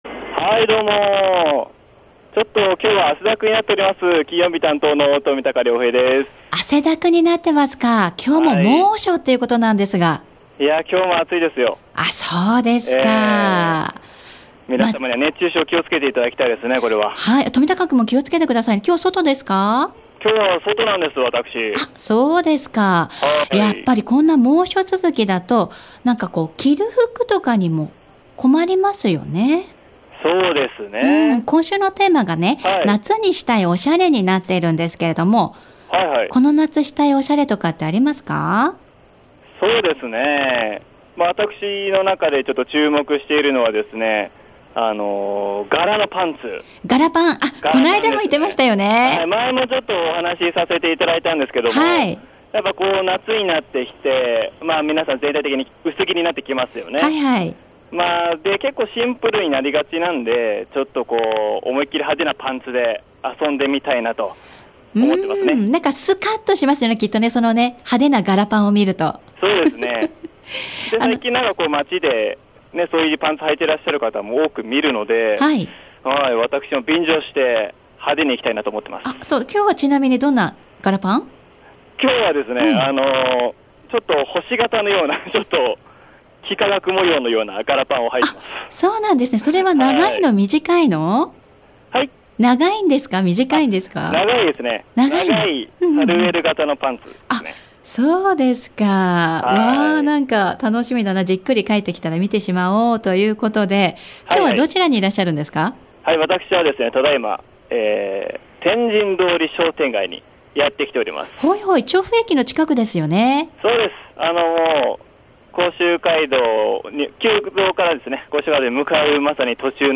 今回は、猛暑の天神通り商店街をレポート☆ということで昼下がりの商店街をプラっとしてきました（＾＾）♪
そして、インタビューに応えてくれました「あずきや安堂」さん！！